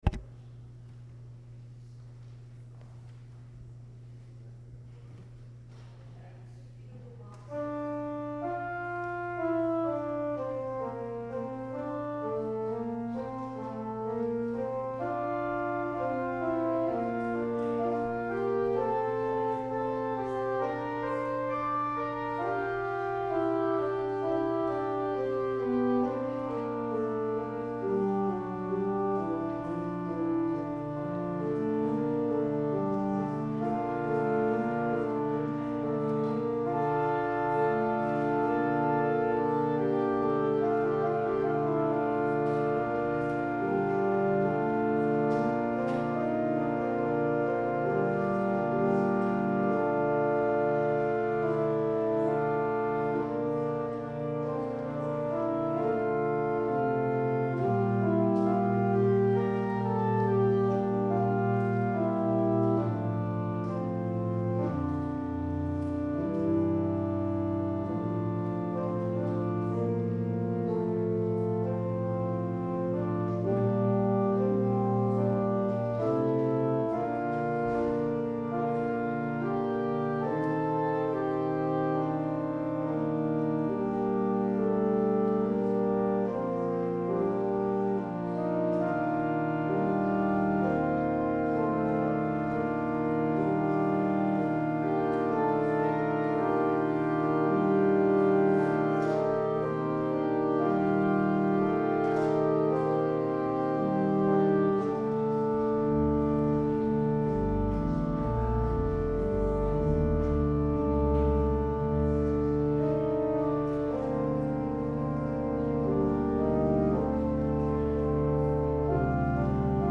Organ music from Sunday Sept. 23, 2018